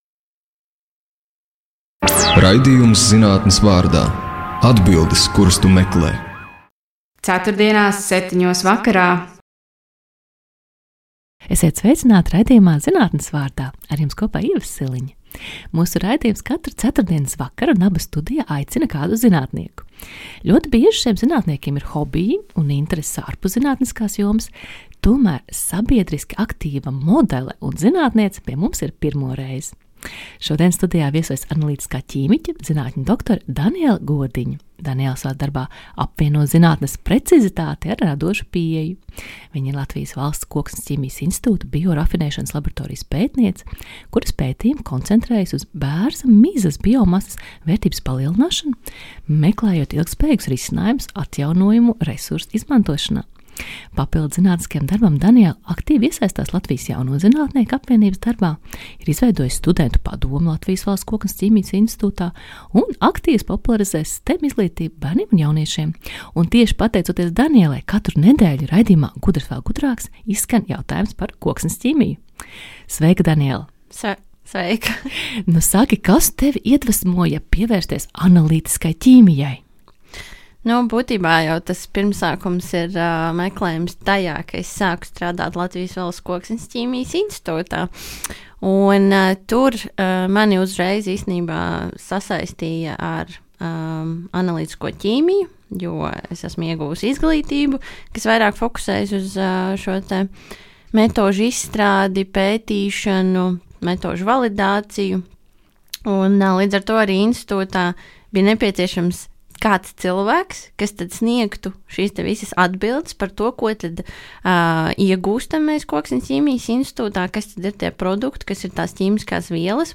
Ķīmijas detektīve. Saruna
Raidījumā tiek iztaujāti zinātnieki par viņu akadēmisko un praktisko ikdienu – lekciju sagatavošanu un pasniegšanu, studentu darbu vadīšanu, sadarbību ar valsts institūcijām vai privātajiem partneriem.